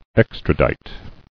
[ex·tra·dite]